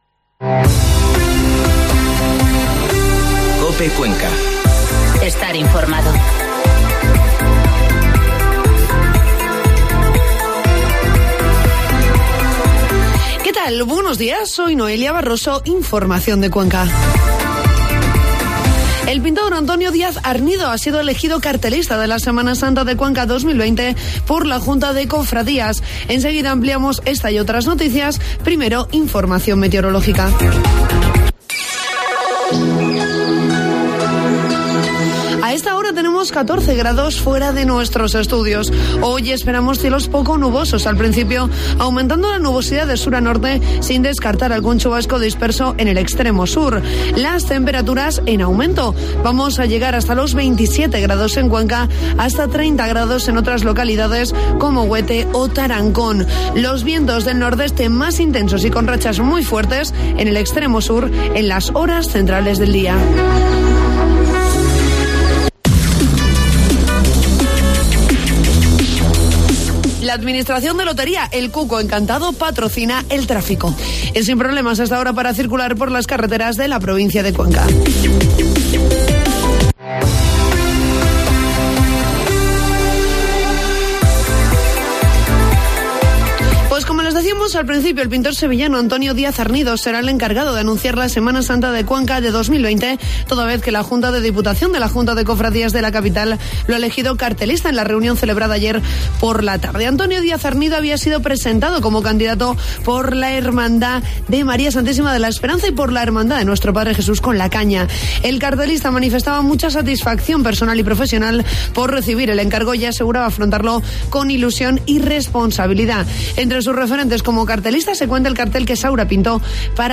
Informativo matinal COPE Cuenca 12 de septiembre